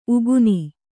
♪ uguni